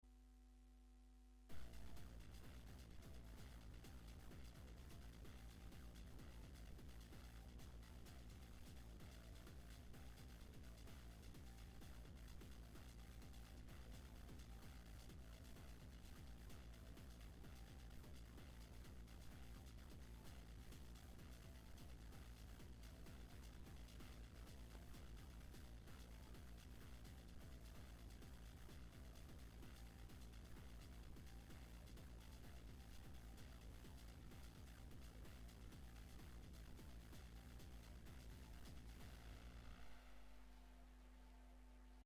Everything here is MS-20. There is one sequence in the second half that was my guitar running through the MS-20 and filtered/modulated. The only effects were some delay and a single reverb on one of the tracks. A somewhat haphazard bit of Ozone was applied to the final result.